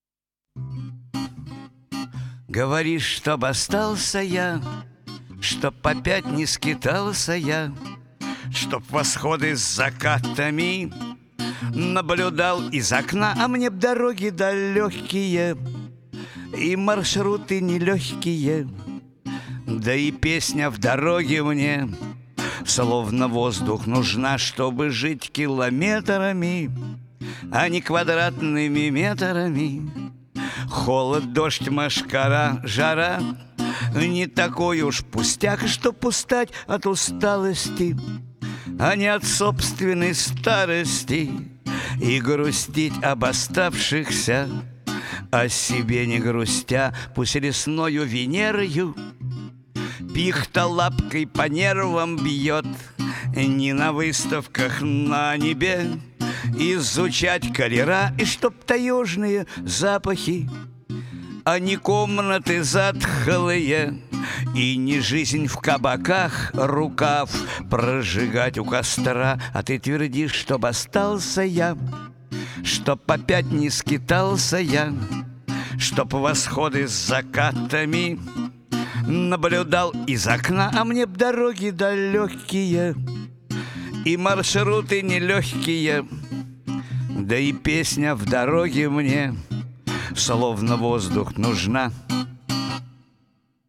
И сопровождение музыкальное так и зовет ехать в путешествие!!!!!!!!!